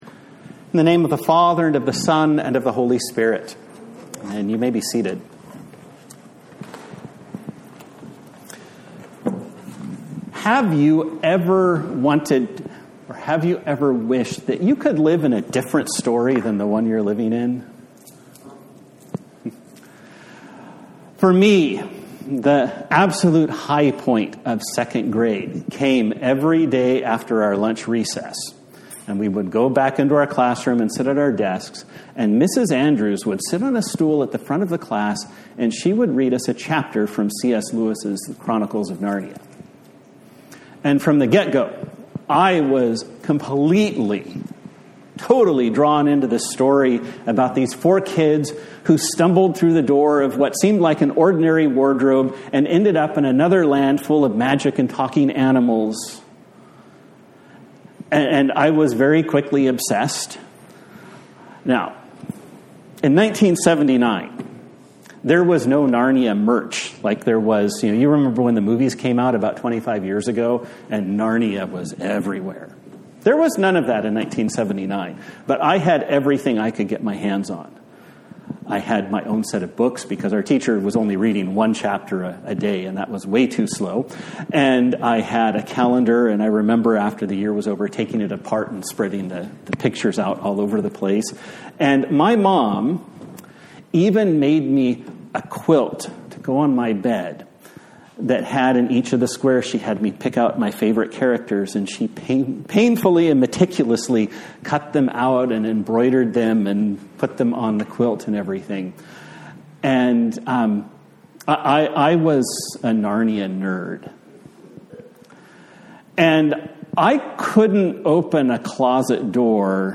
A Sermon for the Epiphany
Passage: Ephesians 3:1-12; Matthew 2:1-12 Service Type: Sunday Morning